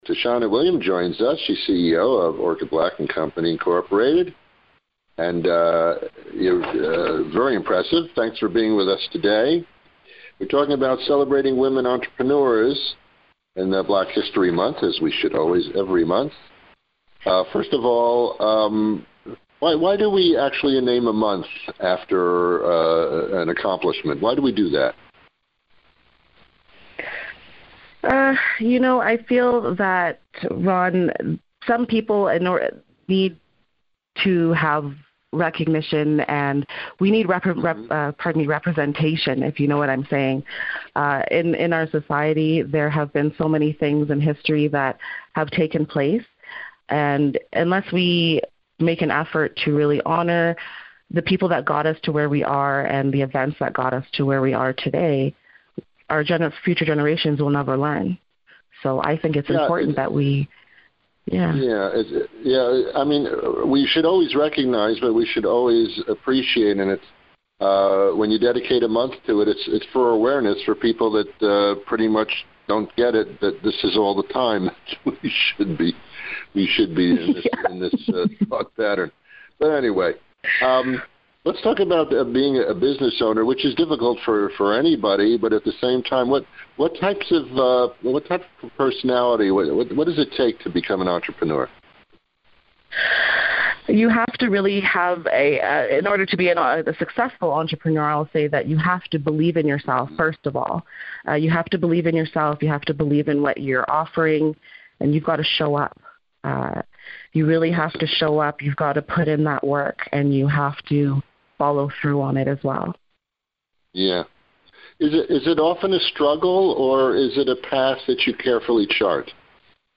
A Radio Media Tour (RMT) is a two-to-four hour block of interviews (typically 10 minutes each) with radio stations across the country.